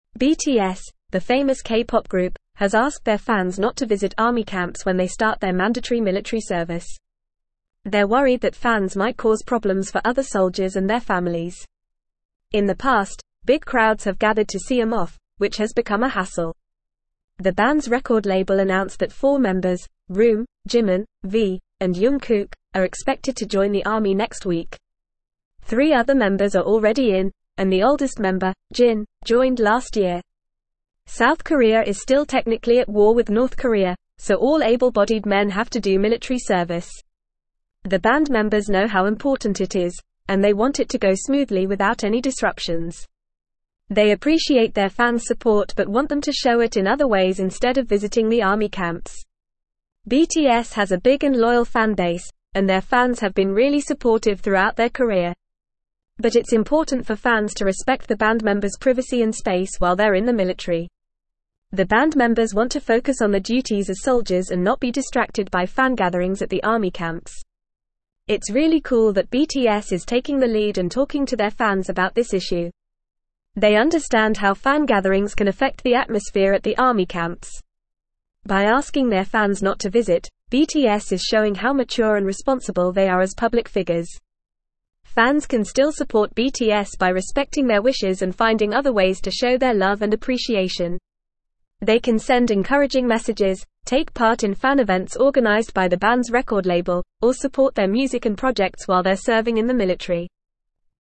Fast
English-Newsroom-Upper-Intermediate-FAST-Reading-BTS-Urges-Fans-to-Stay-Away-from-Army-Camps.mp3